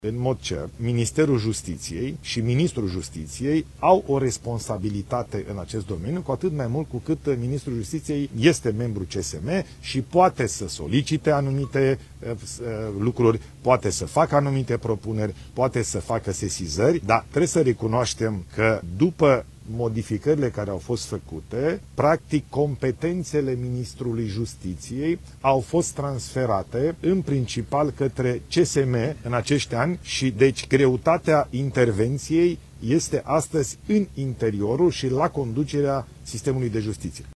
Ilie Bolojan: „Greutatea intervenției este în interiorul și la conducerea sistemului de justiție”